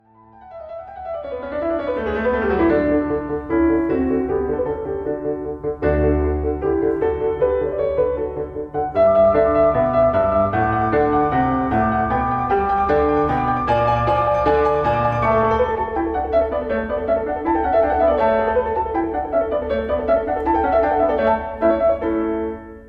Uitgevoerd door Mitsuko Uchida.
Mozart_Klaversonate_D-dur_284_Uchida-Anf.mp3